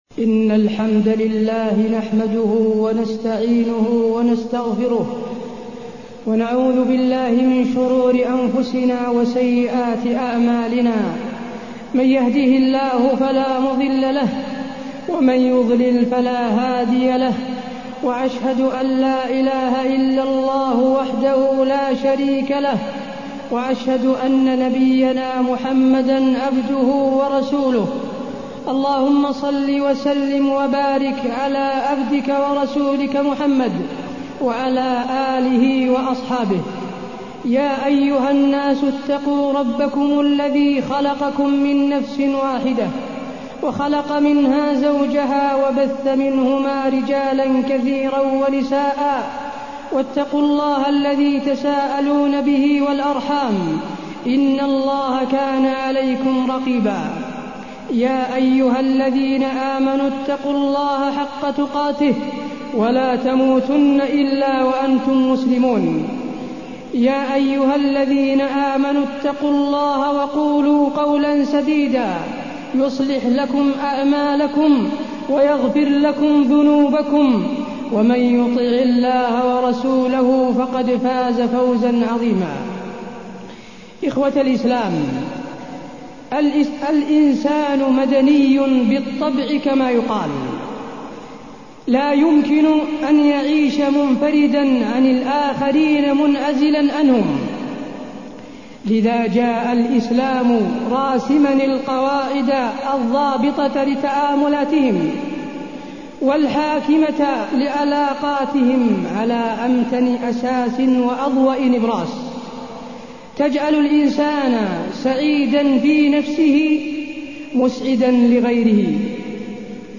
تاريخ النشر ٢٤ جمادى الآخرة ١٤٢١ هـ المكان: المسجد النبوي الشيخ: فضيلة الشيخ د. حسين بن عبدالعزيز آل الشيخ فضيلة الشيخ د. حسين بن عبدالعزيز آل الشيخ حسن المعاملة